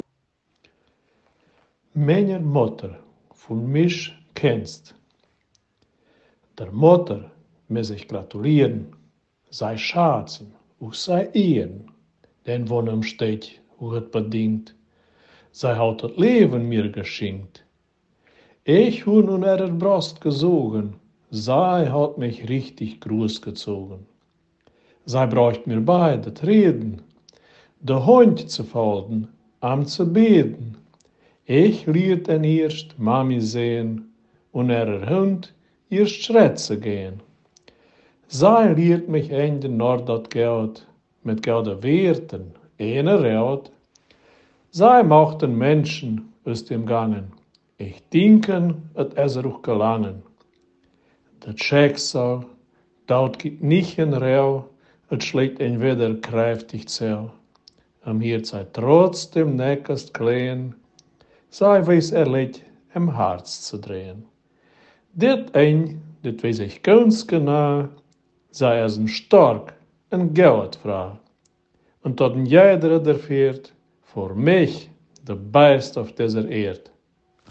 Ortsmundart: Marienburg bei Schäßburg